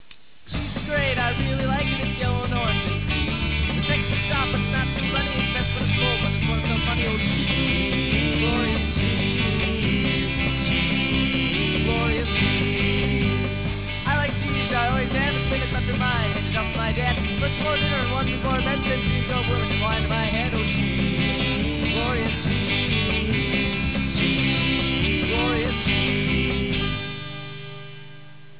drums
lead vocals, bass guitar
They played a mixture of classic and alternative rock.